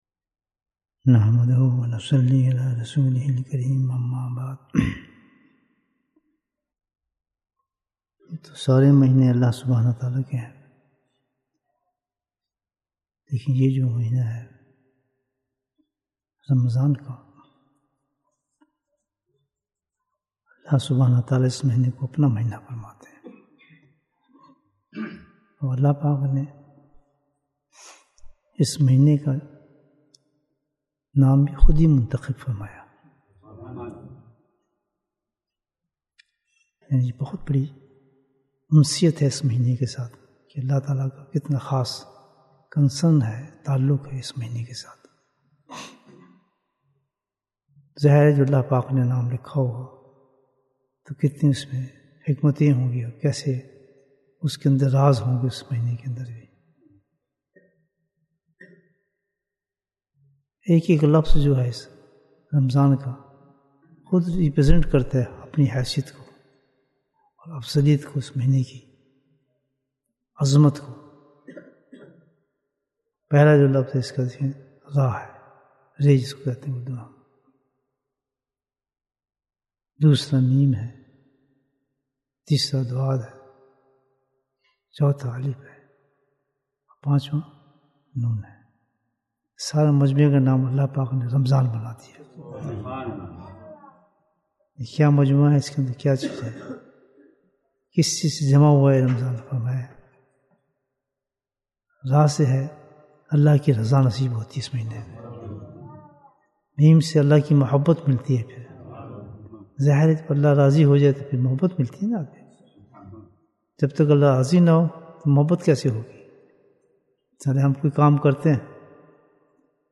Bayan, 38 minutes 13th April, 2022 Click for English Download Audio Comments What is the Secret in the name of Ramadhan?